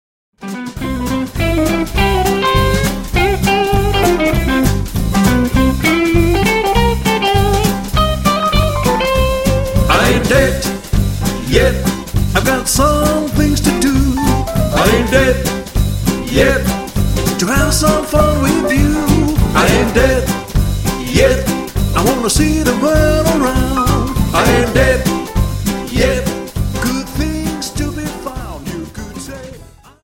Dance: Quickstep 50 Song